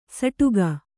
♪ saṭuga